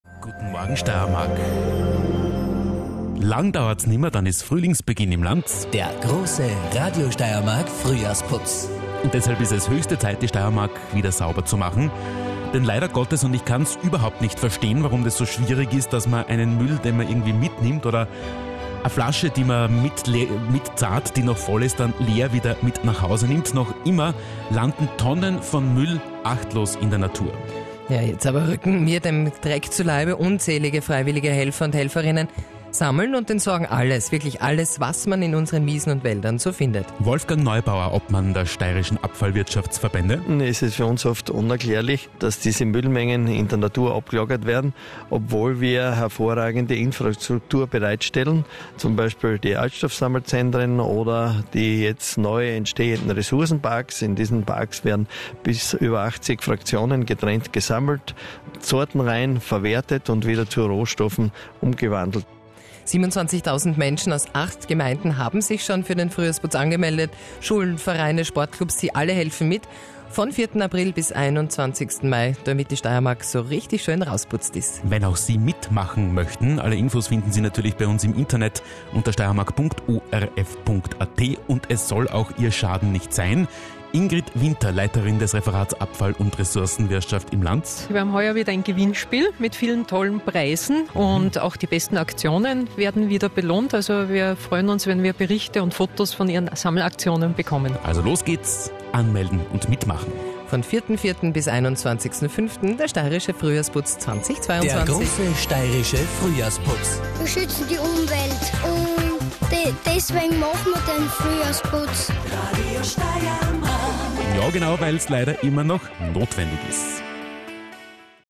Nachfolgend die Hörfunkbeitrage des ORF-Radio Steiermark: